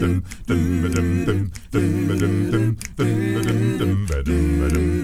ACCAPELLA 1A.wav